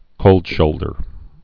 (kōldshōldər)